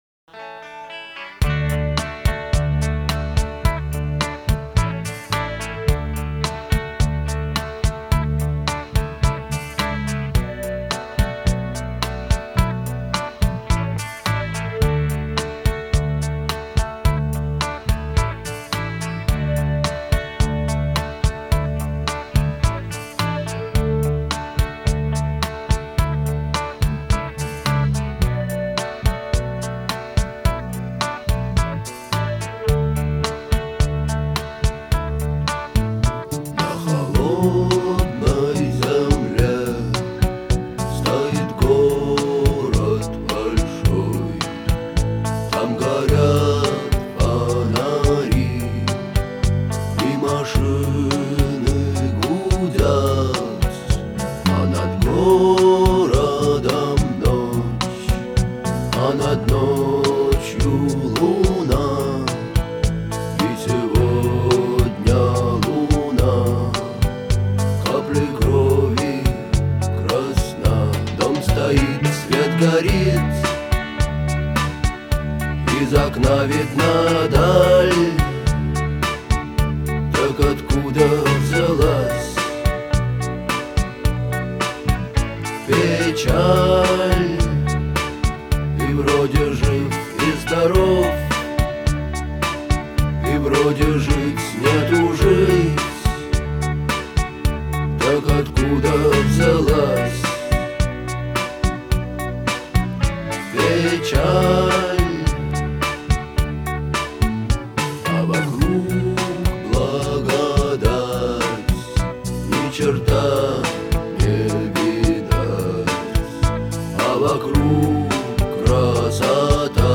это яркий пример постпанка с меланхоличным настроением.